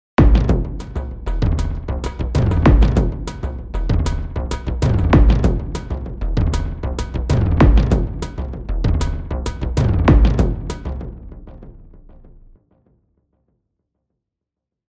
Sample 2 erweist sich als Frame Drum Cave 2 (Sample 1 ist die Framedrum Cave 1).
Hier spielt Sample 2 im Loop-Modus mit kurzem Zeitfenster. Das bewirkt, dass der Loop eine schnelle, einem Wirbel ähnliche Repetition bzw. eine Art Buzz-Effekt auslöst: